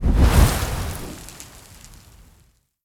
FireIn.wav